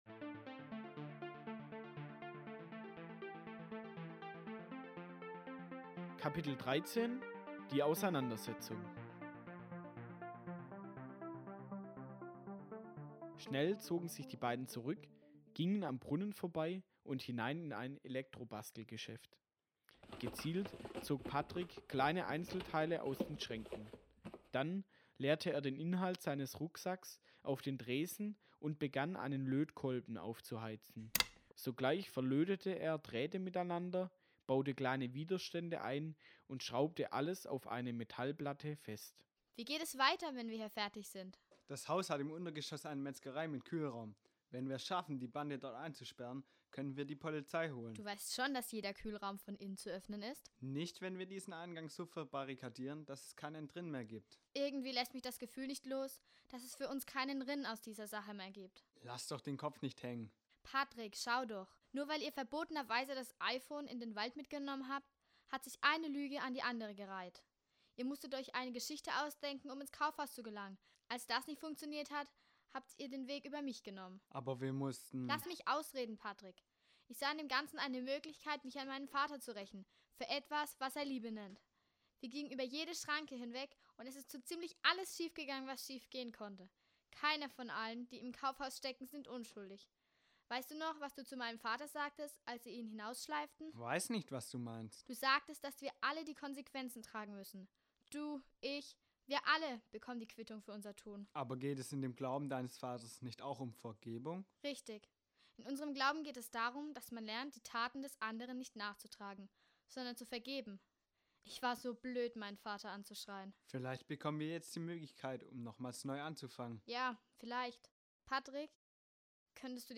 Der Kaufhauskomplott - Hörspiel
Eine spannende Abenteuergeschichte für Kinder zwischen 8 und 88 Jahren, mit viel Liebe zum Detail.